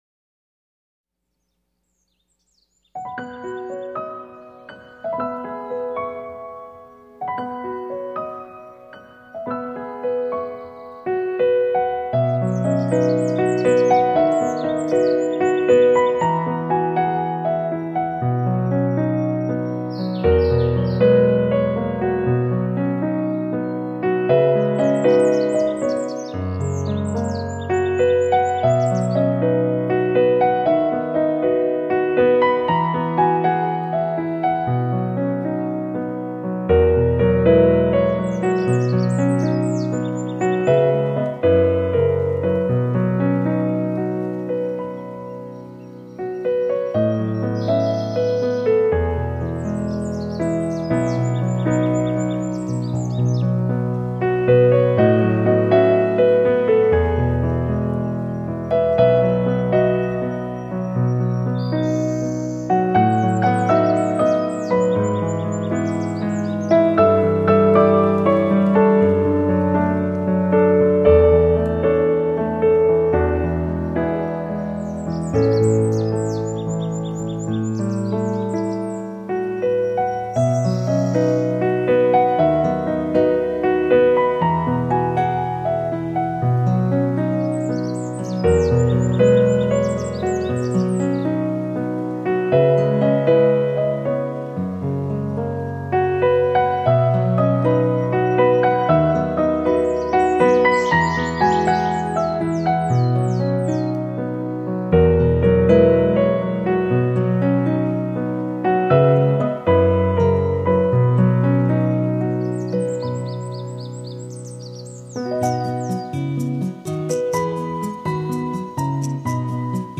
配合著清新规律的稳定节奏
*本CD收录小鸟的鸟叫声、潺潺的流水声和海浪等大自然的声音，具有舒缓身心的疗效。
静心舒畅旋律+大自然天籁
一首舒畅又充满朝气的曲子。